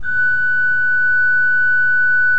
Index of /90_sSampleCDs/Propeller Island - Cathedral Organ/Partition K/KOPPELFLUT R